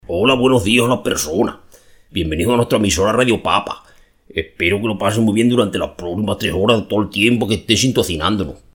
Una idea original para empezar sus programas, poniendo la voz de un personaje famoso en la cabecera.